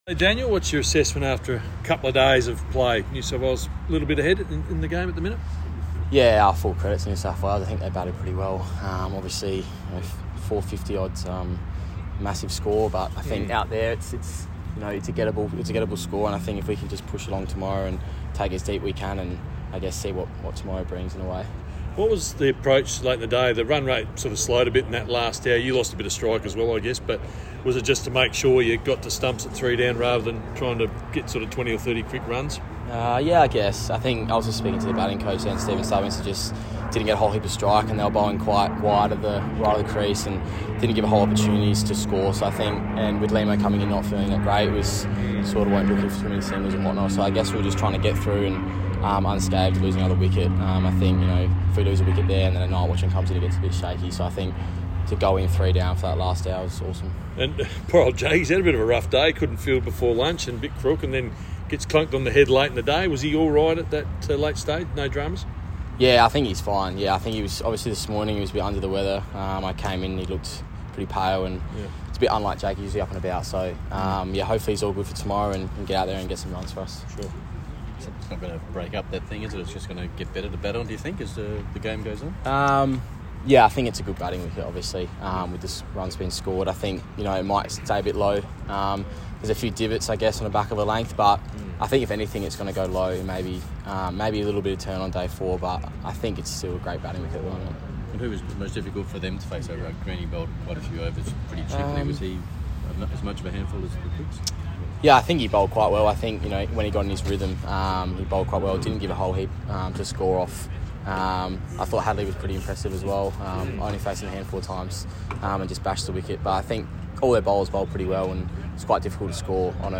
at the end of Day Two against NSW at Karen Rolton Oval.